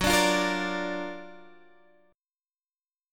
Gb+M7 chord